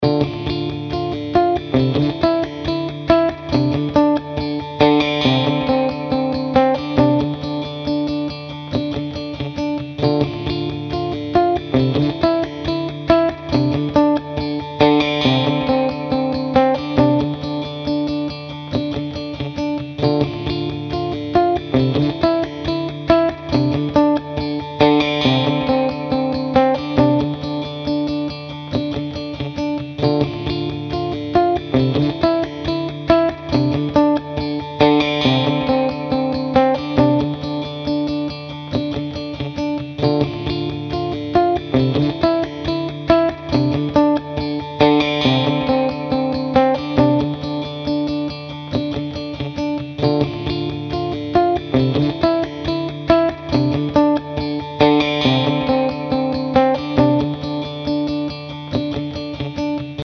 Daher jetzt wirklich eine von meinen Looperaufnahmen aus meinem Ditto-Looper-Pedal. Da dieser vor dem Amp hängt wäre die Aufnahme etwas nackt, daher wurde in Garageband nur eine Ampsimulation und eine kostenlose IR hinzugefügt. Ist auch nur in der DAW ein paar mal geloopt.